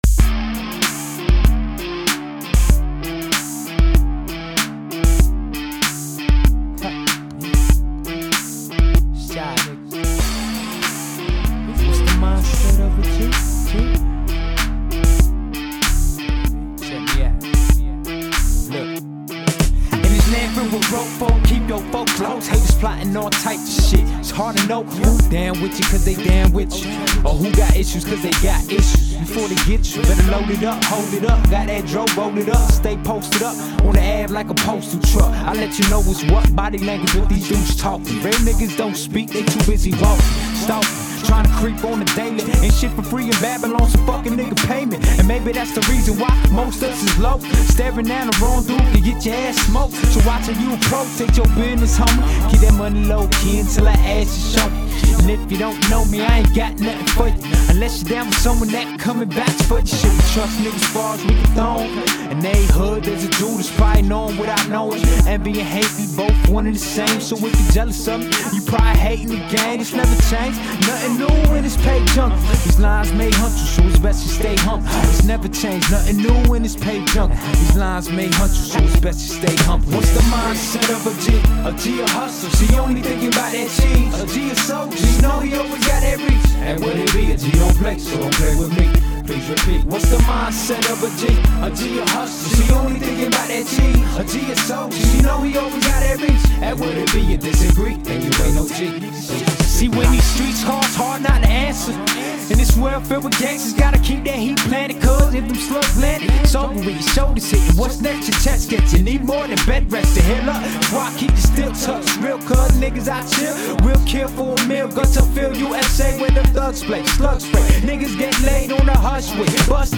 hip hop